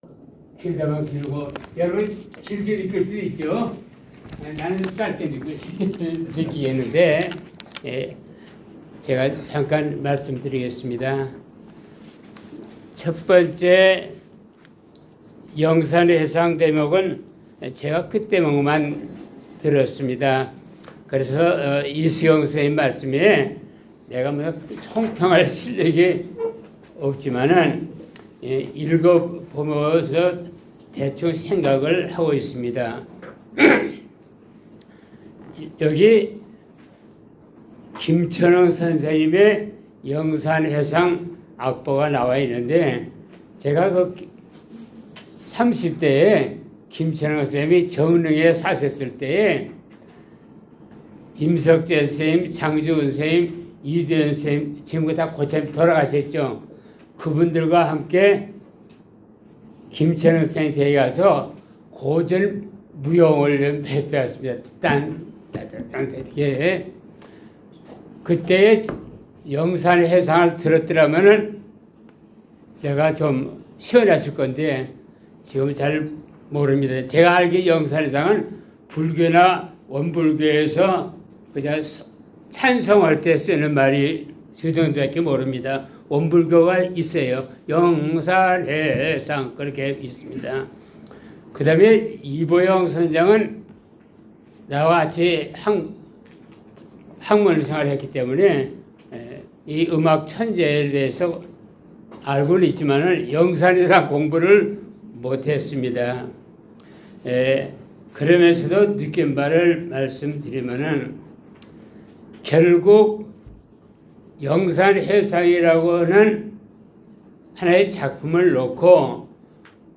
○ 장소 : 국립민속박물관 전통문화배움터